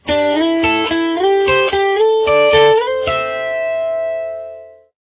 Here are some examples of some licks that you could create with it: